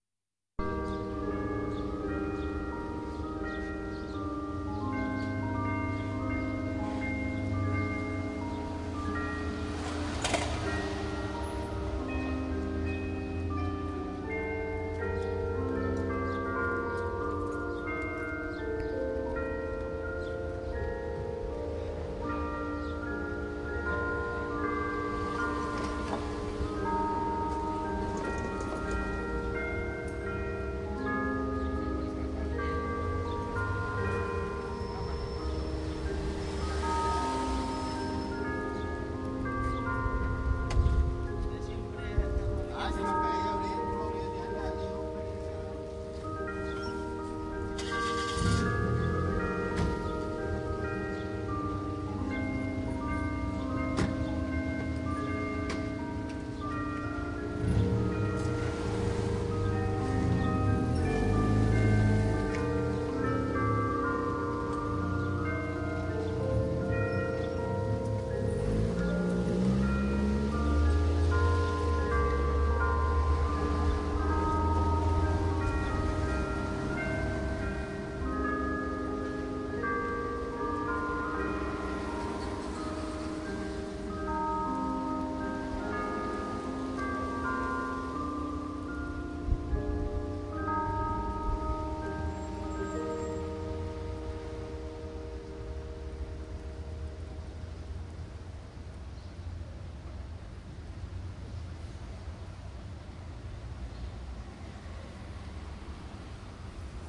描述：一个大教堂内的遥远的合唱，后面跟着一个钟声/coro lejano en el interior de una iglesia, seguido por el tañido de una campana
Tag: 合唱 教堂 现场录制